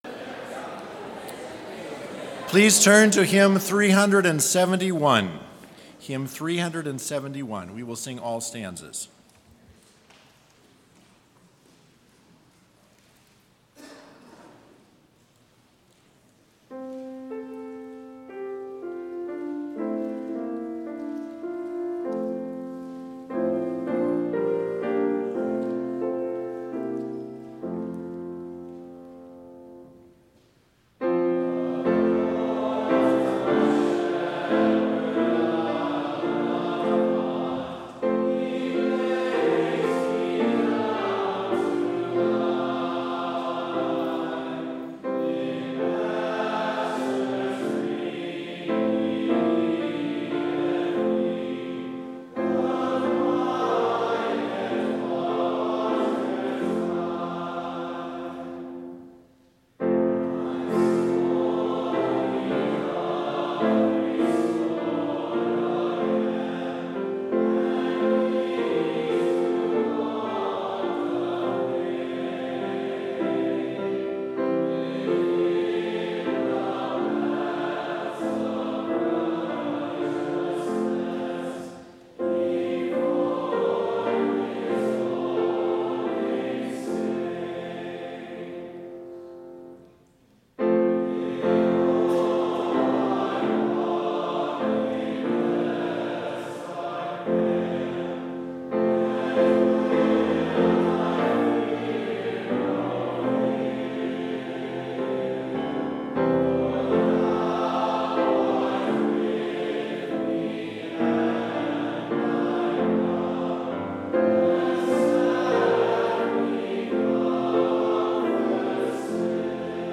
Complete service audio for Chapel - November 21, 2019